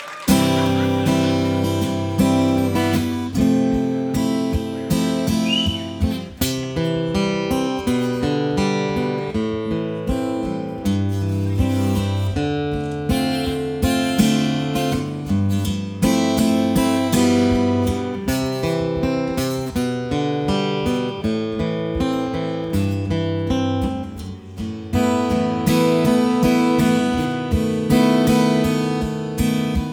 Genre: Musique francophone